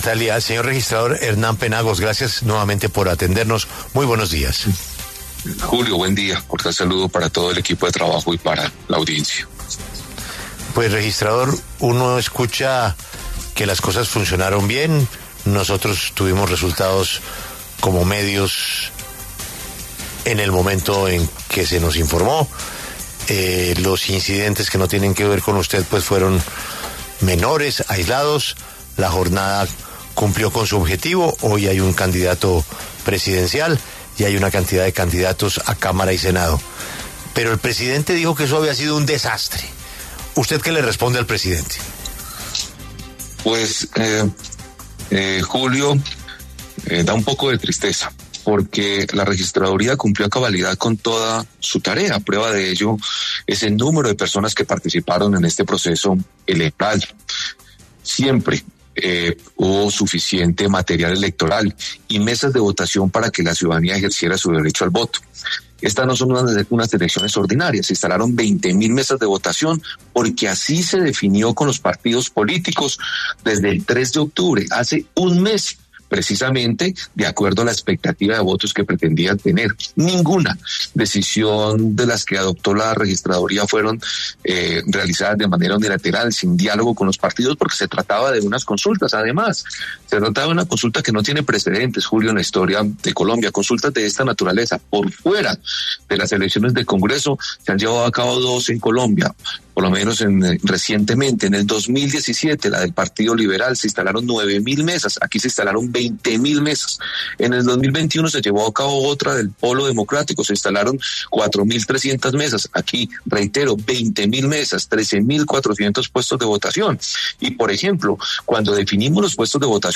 El registrador nacional, Hernán Penagos, pasó por los micrófonos de La W.